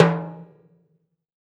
BTIMBALE L1K.wav